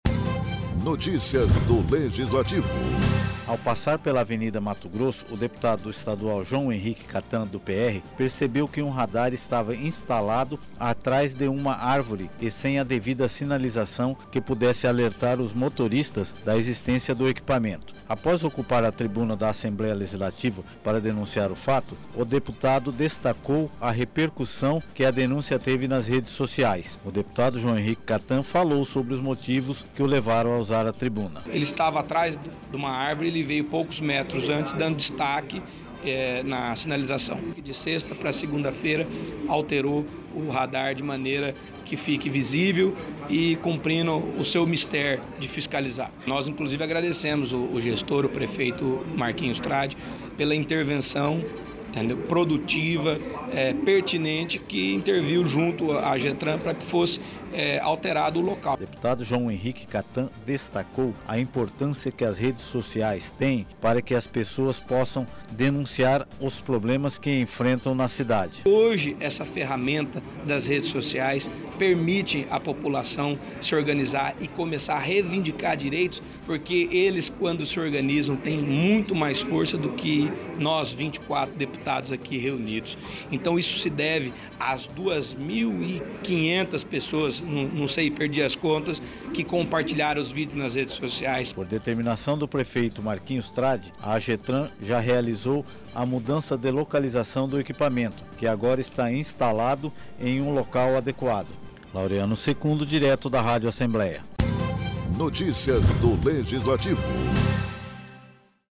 Após ocupar a tribuna da Assembleia Legislativa para denunciar o fato o parlamentar ficou surpreso com a repercussão que a denúncia teve nas redes sociais.
Locução e Produção